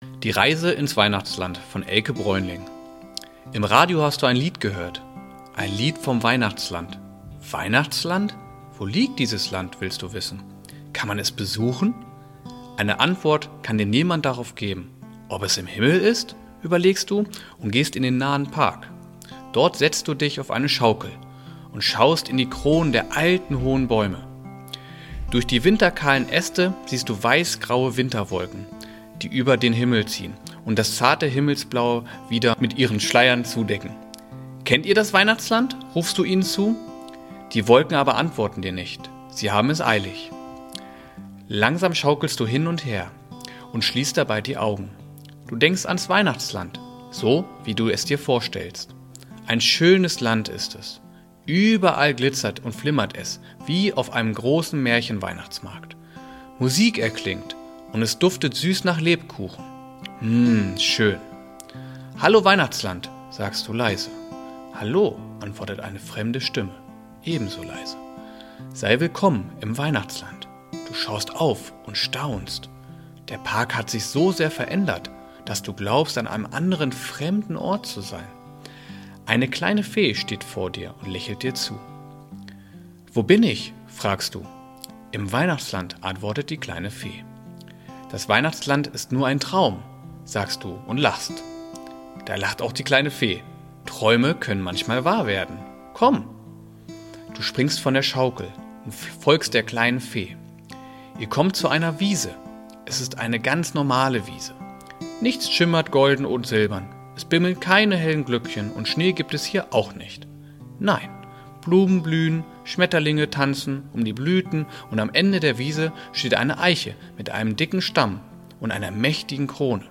adventsgeschichte-4.mp3